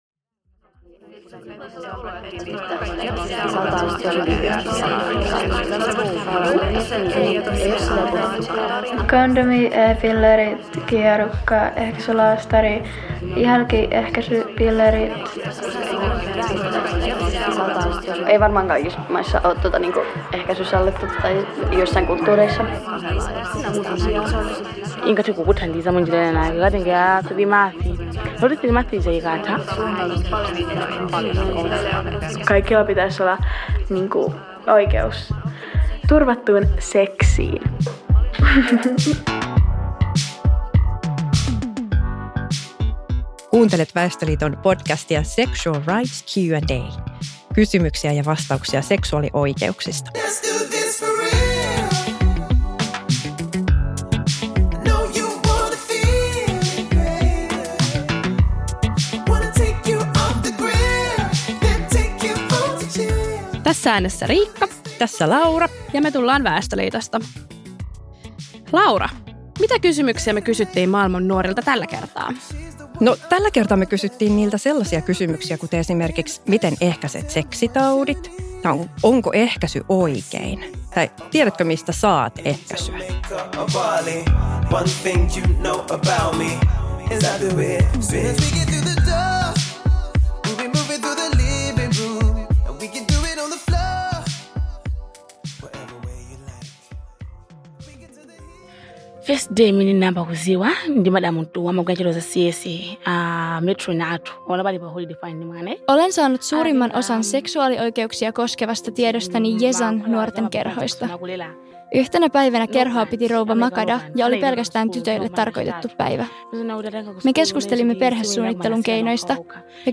Keskustelemassa suomalaisia nuoria, malawilainen ja mosambikilainen nuori
Nuoret vastaavat seksuaaliterveyspalveluihin liittyviin kysymyksiin esimerkiksi ehkäisystä ja sen saatavuudesta.